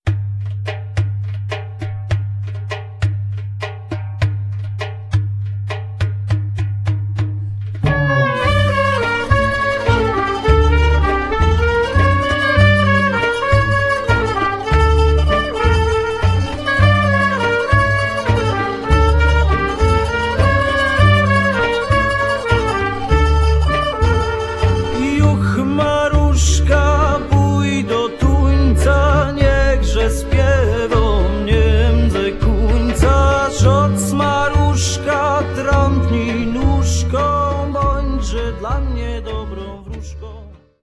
trąbka trumpet, sakshorn altowy alto saxhornes
kontrabas double bass, gitara guitar